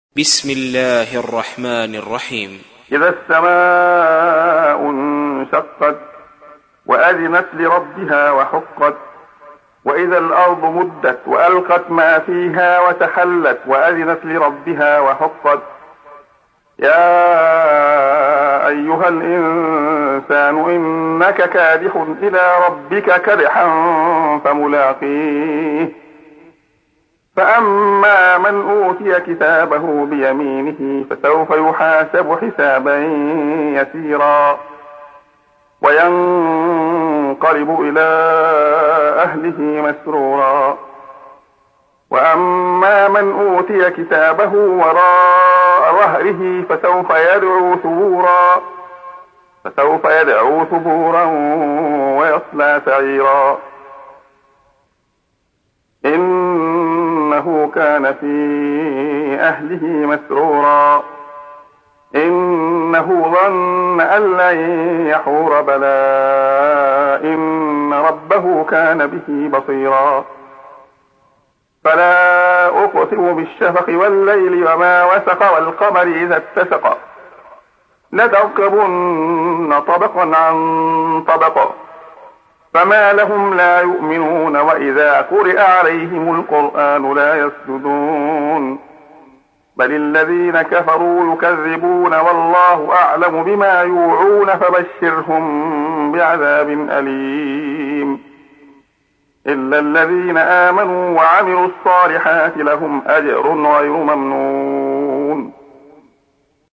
تحميل : 84. سورة الانشقاق / القارئ عبد الله خياط / القرآن الكريم / موقع يا حسين